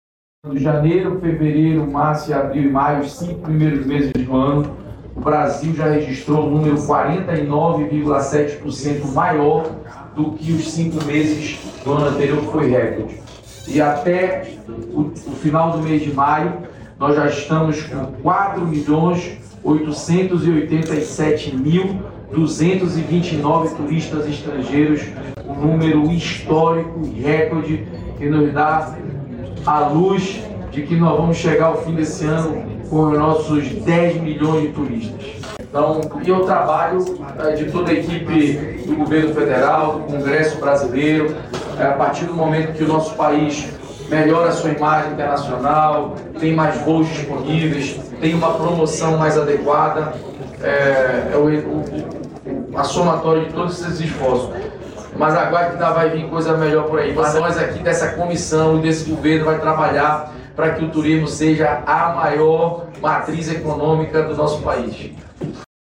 Íntegra dos discursos dos ministros do Desenvolvimento Agrário e Agricultura Familiar, Paulo Teixeira, e da Agricultura e Pecuária, Carlos Fávaro, durante a cerimônia de entrega de máquinas agrícolas a municípios de Minas Gerais no âmbito do Programa Nacional de Modernização e Apoio à Produção Agrícola (PROMAQ), nesta quinta-feira (12), em Contagem (MG).